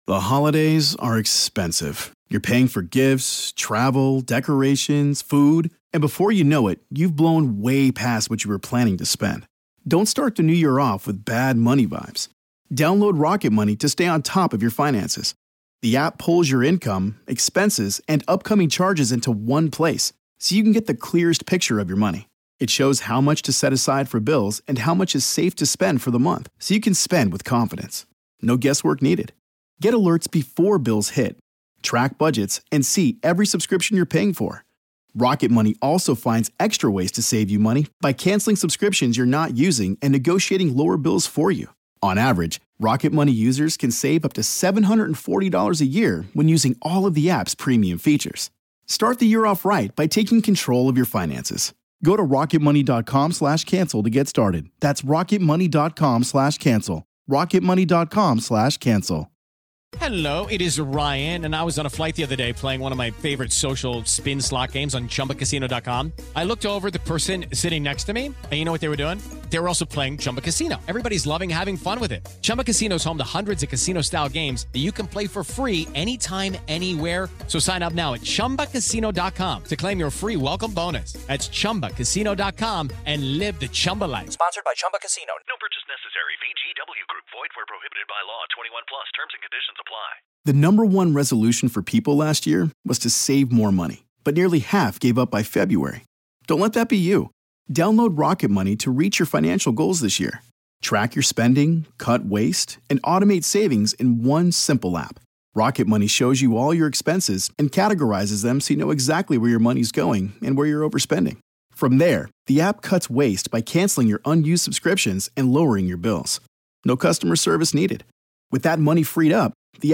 From the best supernatural & paranormal podcast, Real Ghost Stories Online! Haunting real ghost stories told by the very people who experienced these very real ghost stories.